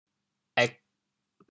êg4.mp3